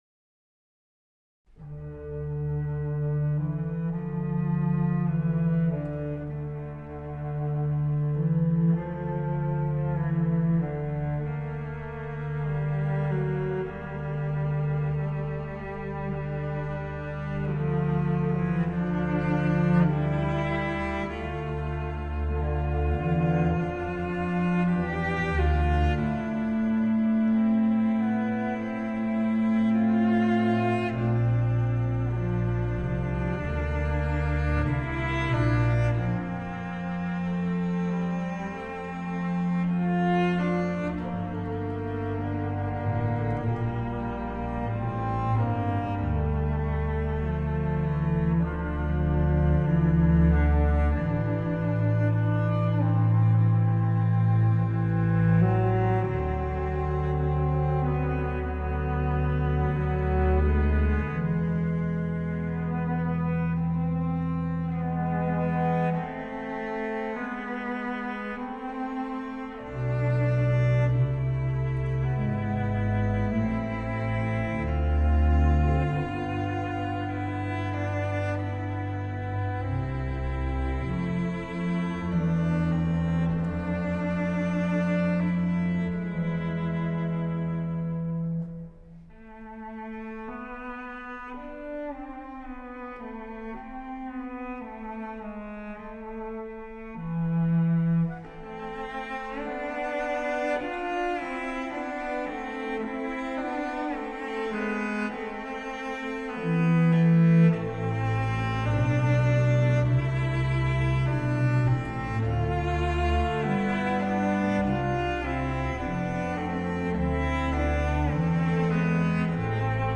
Handel, Conciertos de órgano, Opus 7 4 D adagio